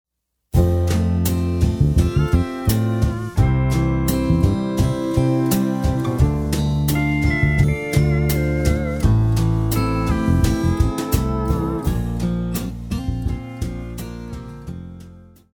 Classical
French Horn
Band
Only backing